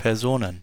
Ääntäminen
Ääntäminen : IPA: [peːɐ̯ˈzoːnən] Haettu sana löytyi näillä lähdekielillä: saksa Käännöksiä ei löytynyt valitulle kohdekielelle. Personen on sanan Person monikko.